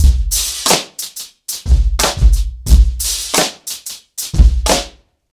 • 90 Bpm Drum Beat C# Key.wav
Free drum groove - kick tuned to the C# note. Loudest frequency: 2653Hz
90-bpm-drum-beat-c-sharp-key-CaH.wav